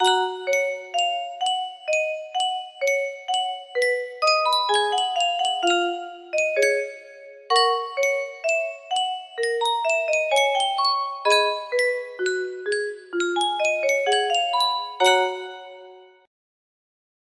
Yunsheng Music Box - Bach Air on the G String 1154 music box melody
Full range 60